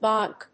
/bάŋk(米国英語), bˈɔŋk(英国英語)/